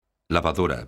lavadora_son.mp3